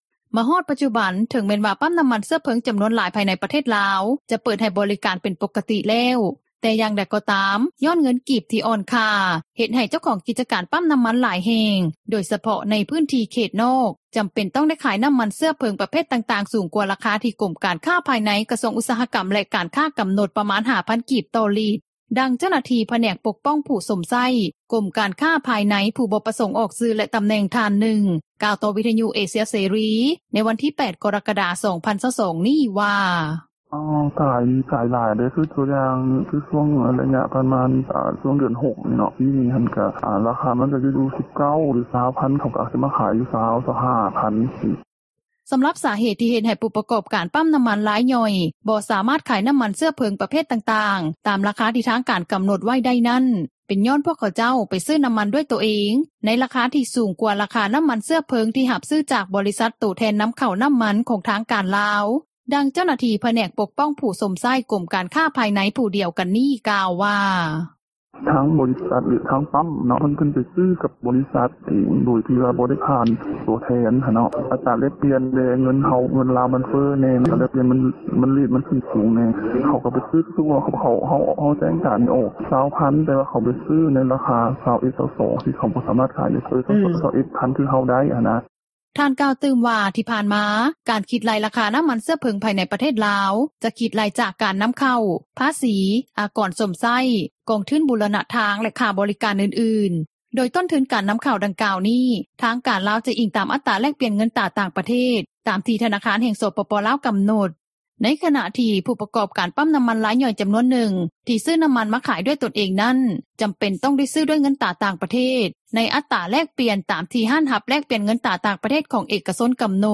ດັ່ງເຈົ້າໜ້າທີ່ ຜແນກປົກປ້ອງຜູ້ຊົມໃຊ້ ກົມການຄ້າພາຍໃນ ຜູ້ບໍ່ປະສົງອອກຊື່ແລະຕໍາແໜ່ງ ທ່ານນຶ່ງ ກ່າວຕໍ່ວິທຍຸເອເຊັຽເສຣີ ໃນວັນທີ 8 ກໍຣະກະດາ 2022 ນີ້ວ່າ:
ດັ່ງຊາວບ້ານ ຢູ່ບ້ານຊຽງດາ ເມືອງໄຊເສດຖາ ນະຄອນຫຼວງວຽງຈັນ ນາງນຶ່ງ ກ່າວວ່າ: